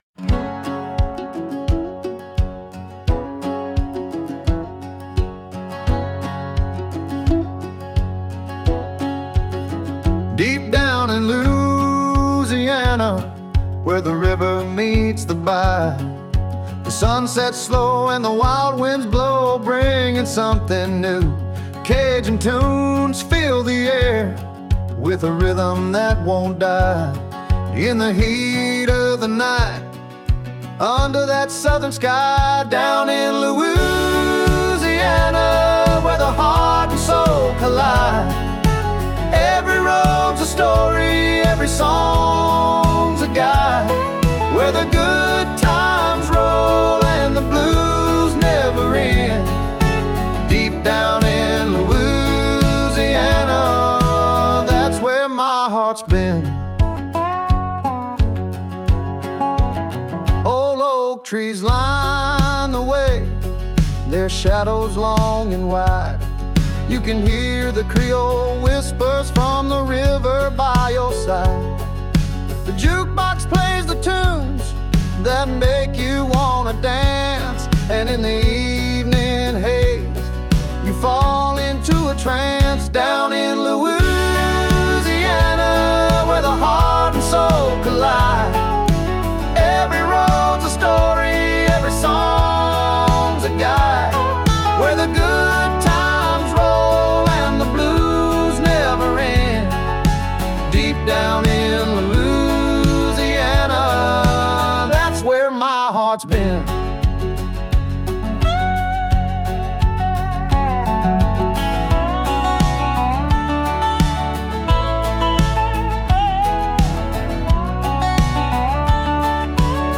Genre:                   Southern Blues Rock